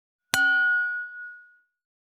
317,ガラスのグラス,ウイスキー,コップ,食器,テーブル,チーン,カラン,キン,コーン,チリリン,カチン,チャリーン,クラン,カチャン,クリン,シャリン,チキン,コチン,カチコチ,チリチリ,シャキン,
コップ